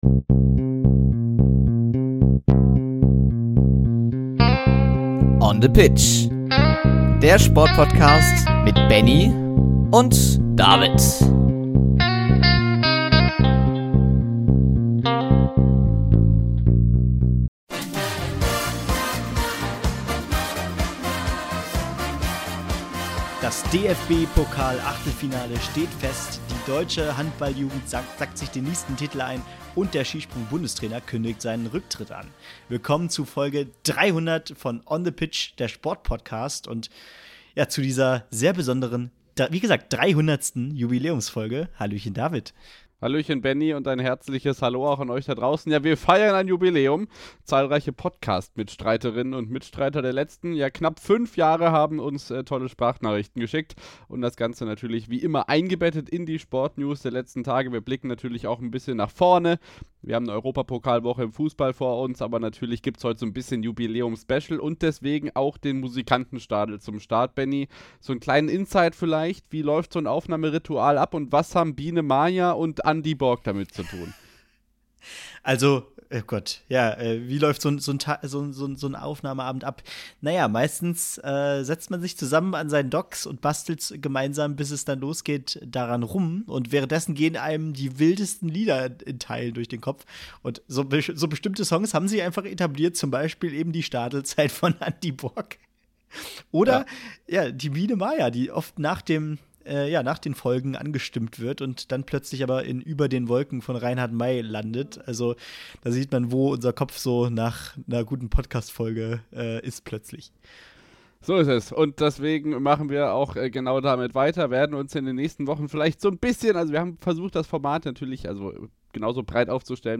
In dieser Folge blicken wir nicht nur zurück auf die besonderen Momente der letzten fast fünf Jahre, sondern liefern auch die aktuellsten Sport-News aus Fußball, Handball, Wintersport, Tennis, NBA, NFL, Leichtathletik, Darts, Snooker, Radsport und Motorsport. Besonders: Wir haben neun persönliche Grußbotschaften von Mitstreitern, Wegbegleitern und sogar ein bisschen Prominenz eingebaut – kleine Stimmen, die die Geschichte von On the Pitch mitprägen und diese Jubiläumsfolge zu etwas ganz Besonderem machen. Darüber hinaus gibt es einen Blick in die Zukunft des Podcasts: Wir verraten, wie sich das Format künftig entwickeln wird.